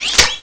scan_activate.wav